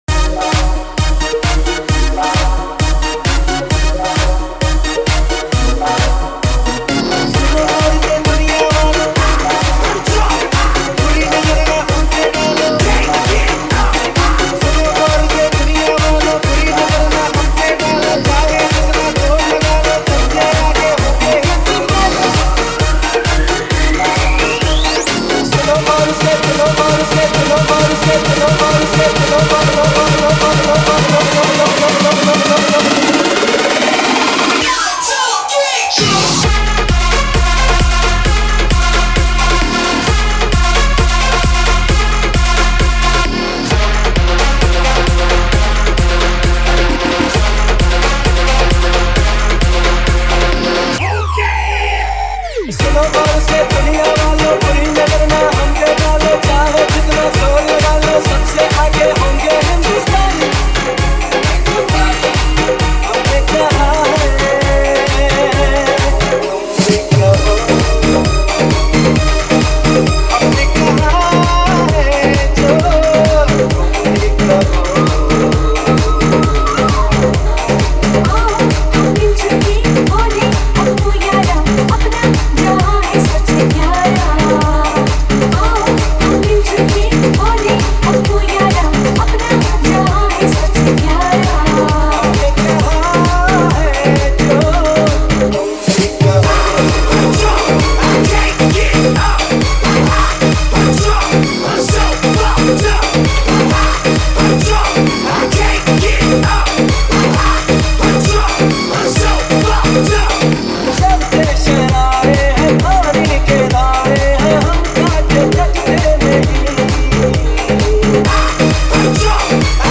Desh Bhakti Dj Remix Song Songs Download